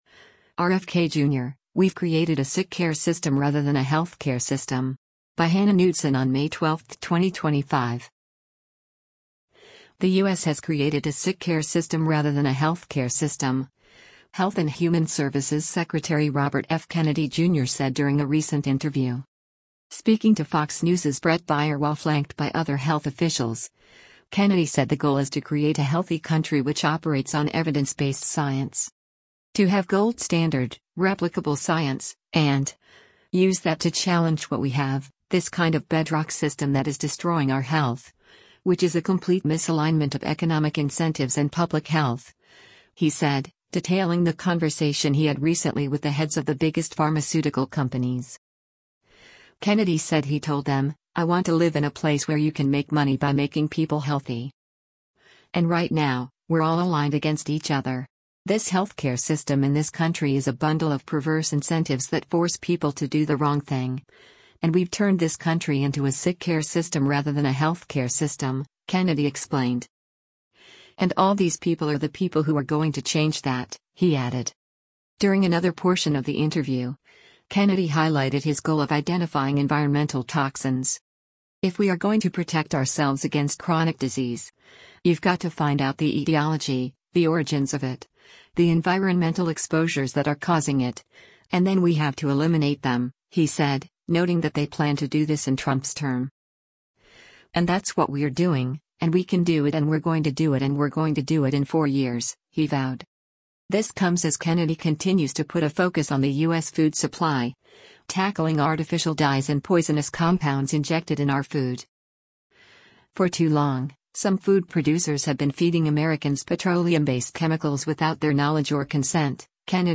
The U.S. has created a “sick-care system” rather than a healthcare system, Health and Human Services Secretary Robert F. Kennedy Jr. said during a recent interview.
Speaking to Fox News’s Bret Baier while flanked by other health officials, Kennedy said the goal is to create a healthy country which operates on “evidence-based science.”